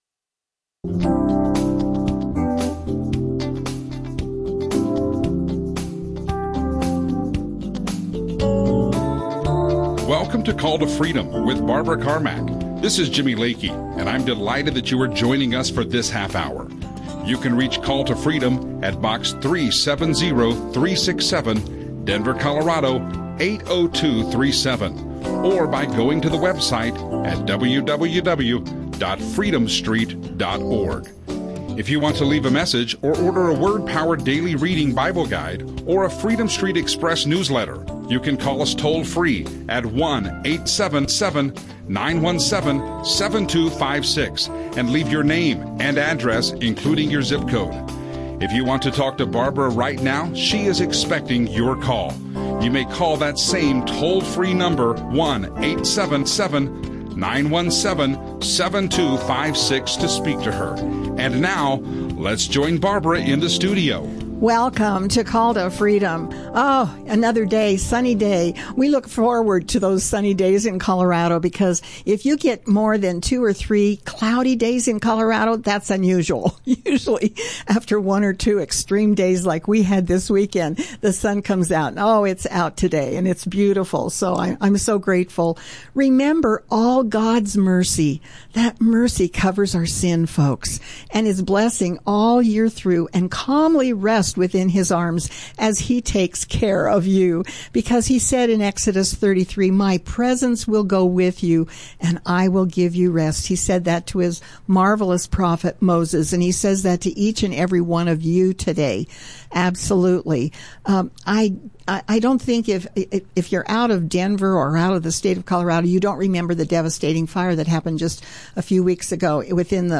Audio teachings
Christian radio